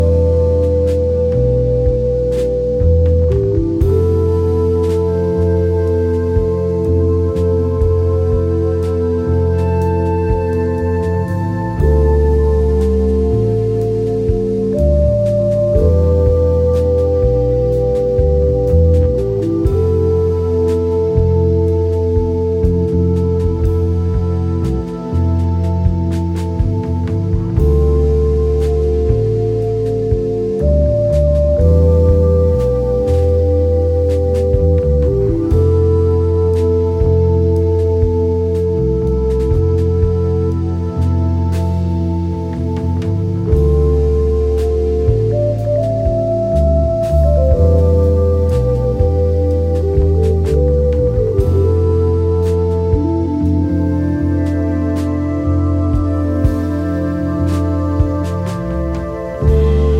今回は、スイスの高地にあり、高級スキーリゾート地として知られる村レザンで6日間かけて録音したという作品。
清涼感や浮遊感を纏ったクールな現代ジャズ/フュージョンを繰り広げています。